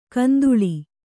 ♪ kanduḷi